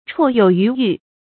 绰有余裕 chuò yǒu yú yù 成语解释 形容态度从容，不慌不忙的样子。